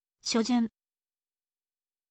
shojun